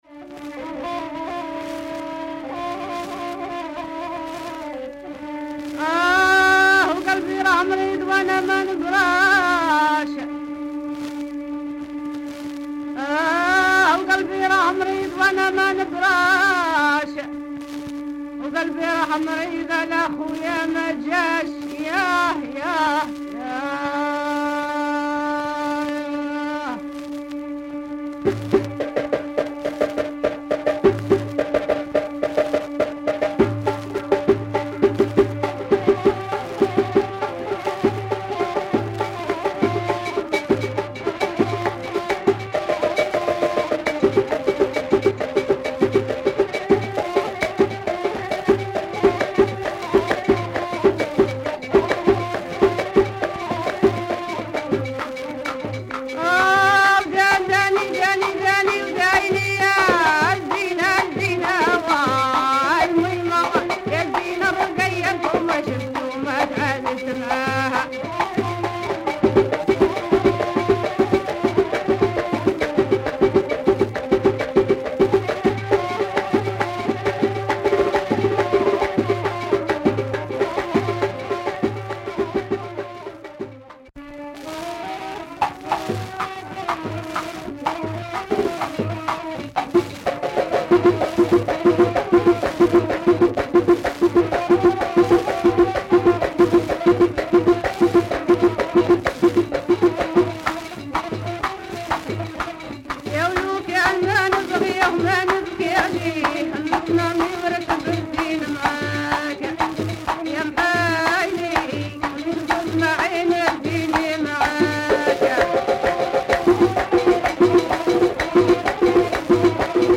Raw female chants from Algeria